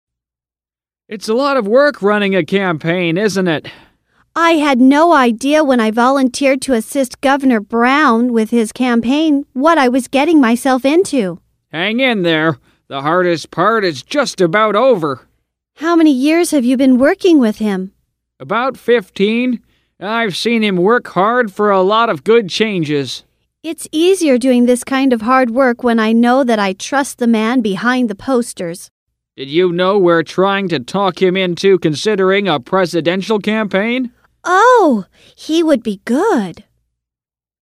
dialogue
英语情景对话